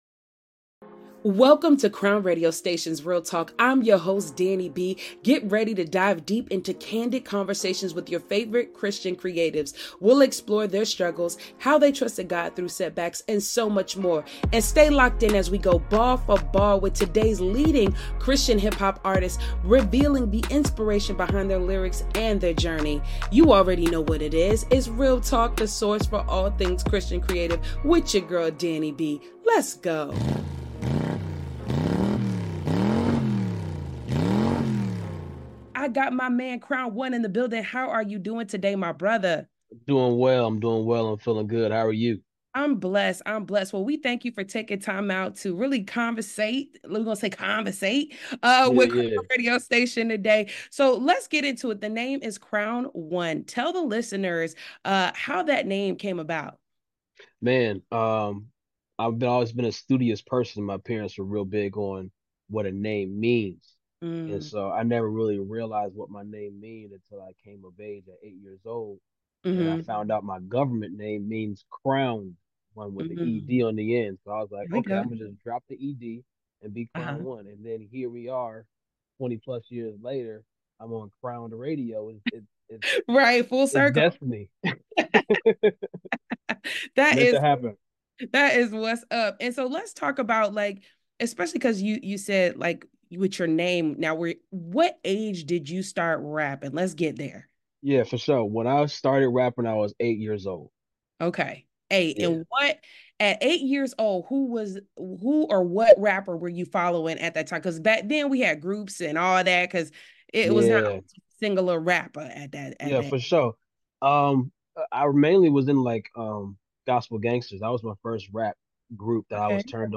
Exclusive Interview w